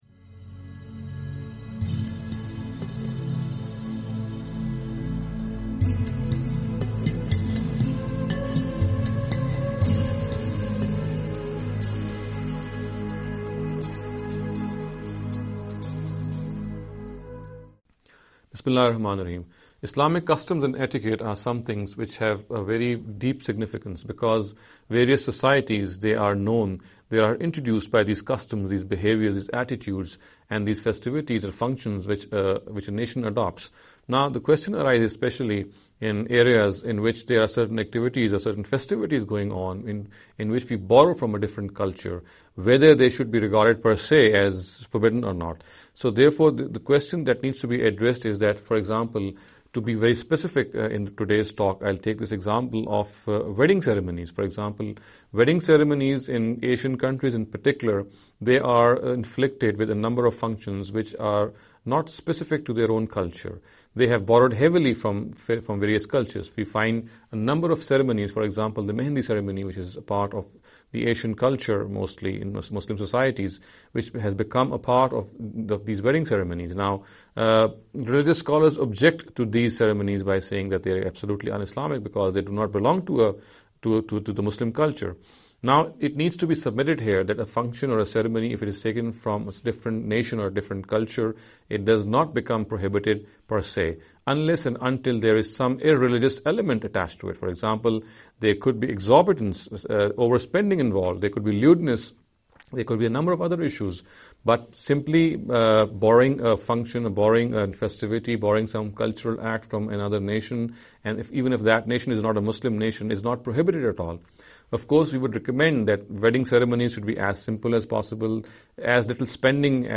This lecture series will deal with some misconception regarding the Islamic Customs & Etiquette. In every lecture he will be dealing with a question in a short and very concise manner.